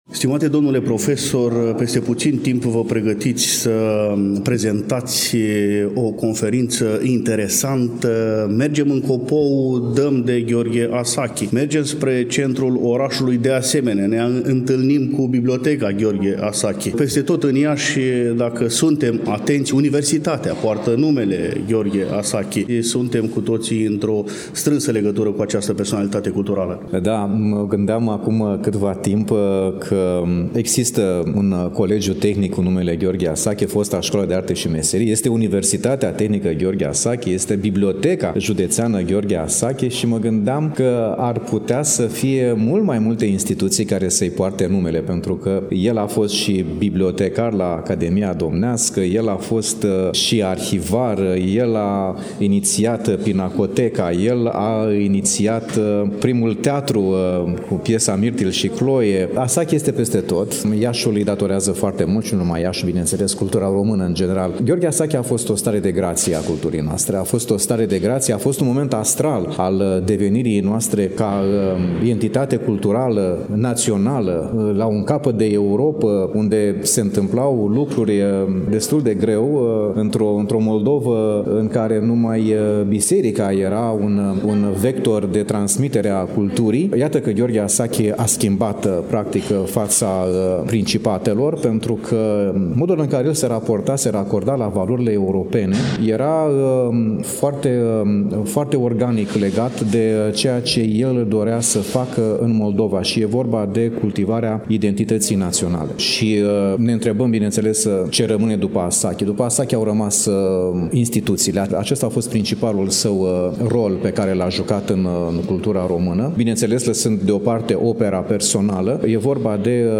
Urmașii lui Gheorghe Asachi au jucat, de-a lungul deceniilor, un rol important pe tărâmul culturii noastre. Despre toate acestea, aflăm amănunte din următorul dialog.